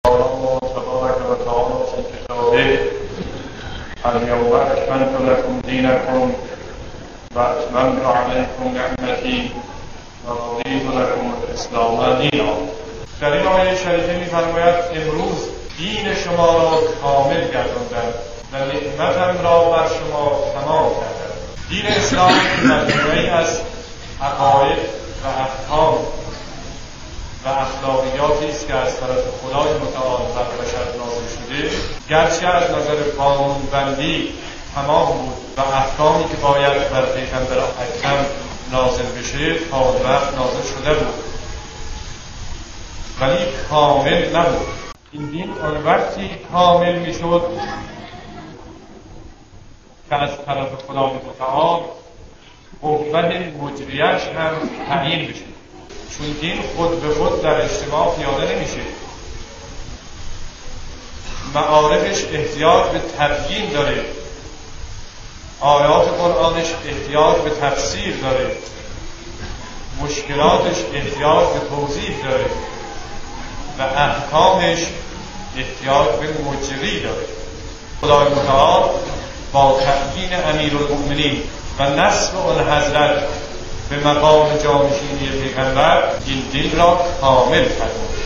به گزارش خبرگزاری حوزه، مرحوم علامه مصباح در یکی از سخنرانی‌ها به موضوع «اکمال دین و اتمام نعمت در روز غدیر» اشاره کردند که تقدیم شما فرهیختگان می‌شود.